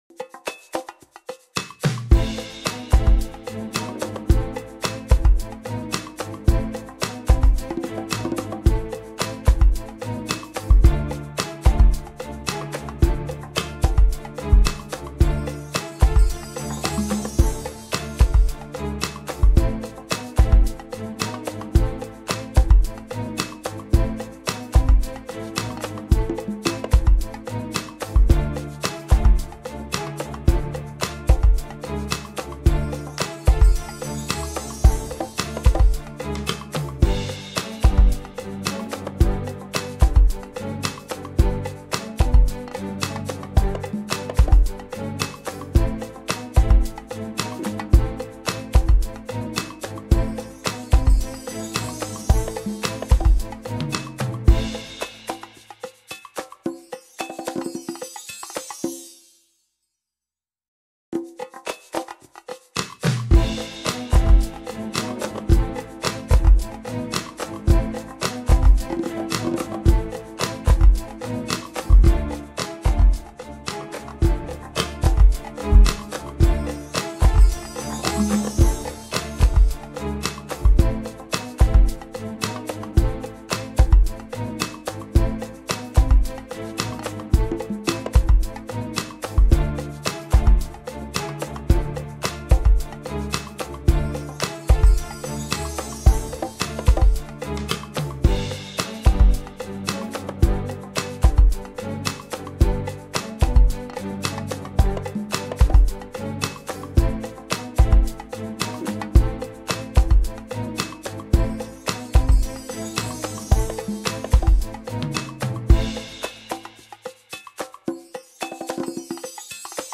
Ведёт эфир Татьяна Фельгенгауэр